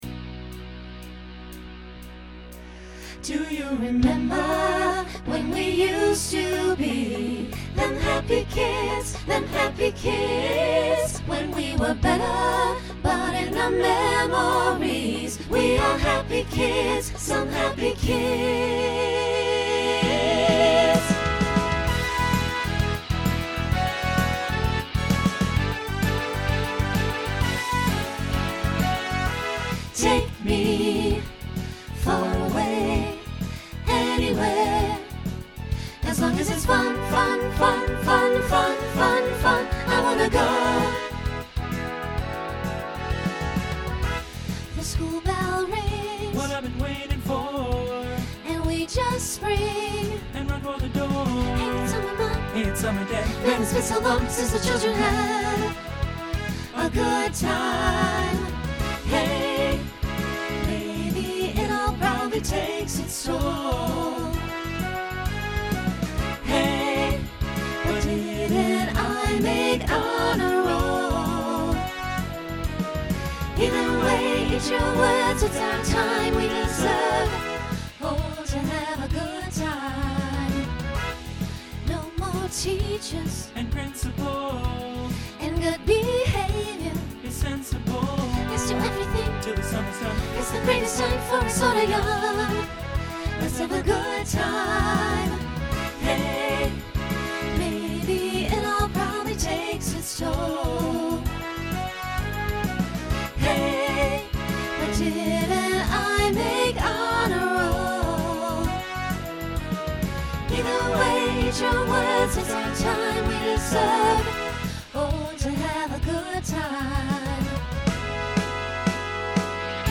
Genre Broadway/Film , Pop/Dance Instrumental combo
Voicing SATB